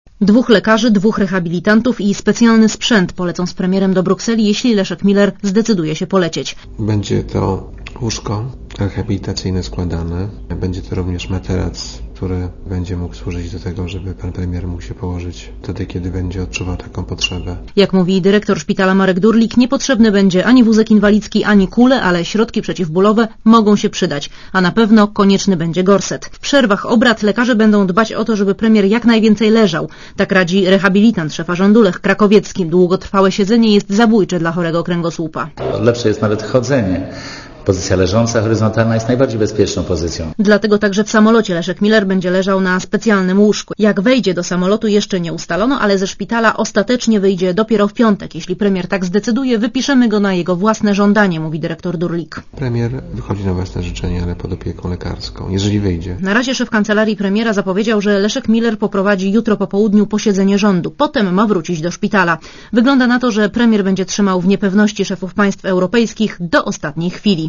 Komentarz audio (264Kb)